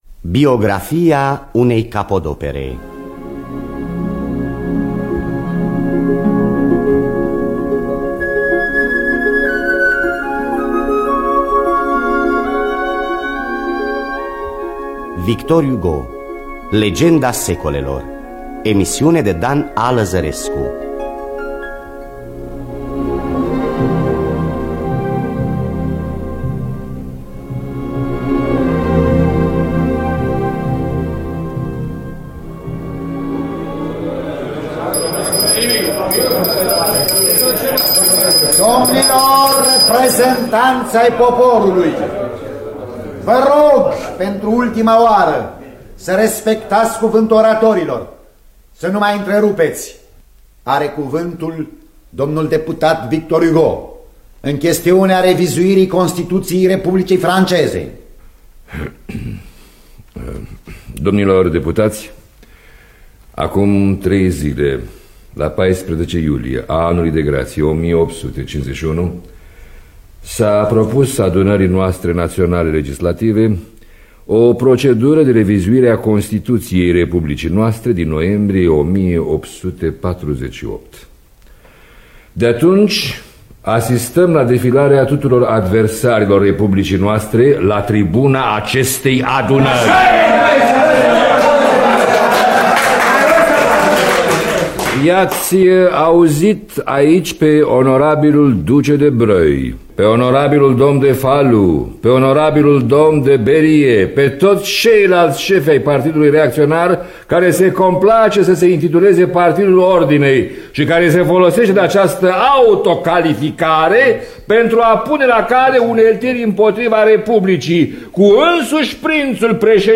Biografii, memorii: “Legenda secolelor” de Victor Hugo. Scenariu radiofonic de Dan Amedeo Lăzărescu.